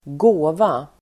Uttal: [²g'å:va]